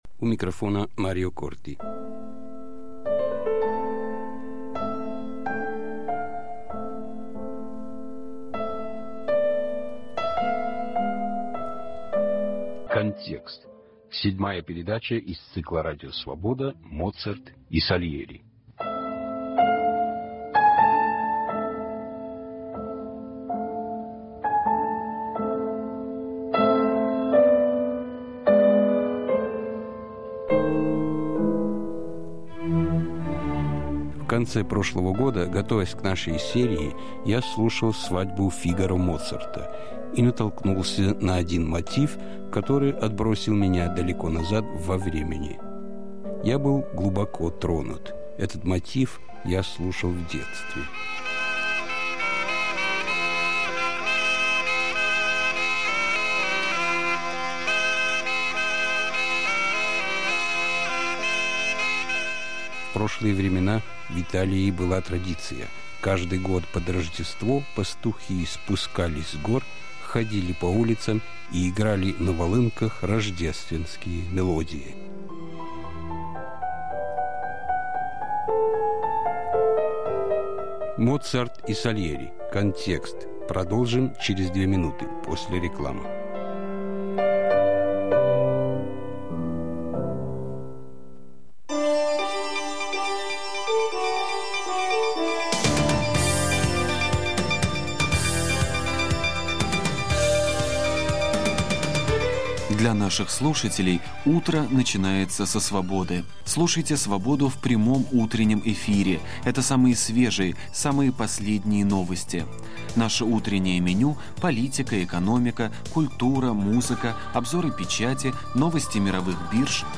Моцарт и Сальери. Историко-музыкальный цикл из девяти передач, 1997 год.